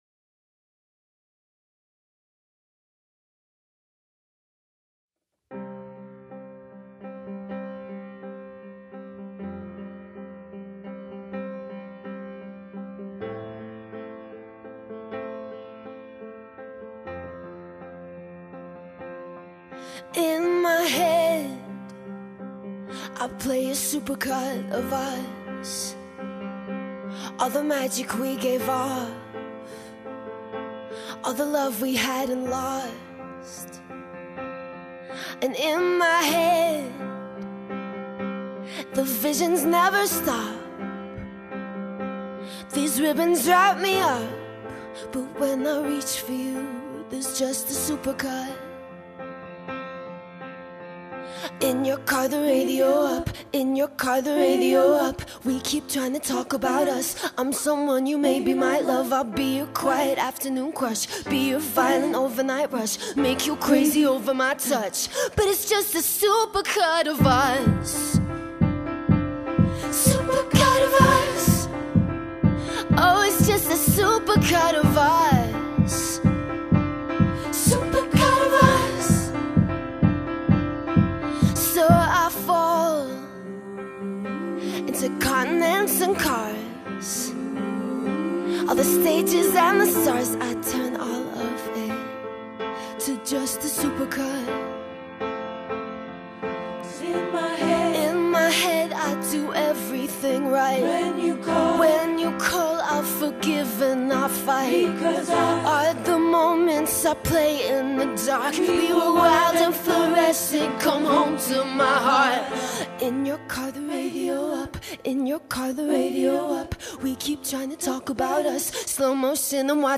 Acoustic version
at Electric Lady Sound Studios